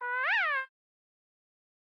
* Scurret audio tuning * Add new sfx * Update sneezing sfx * YAML support * Rename a folder 2025-07-03 17:28:44 +02:00 20 KiB Raw History
wawa_achoo.ogg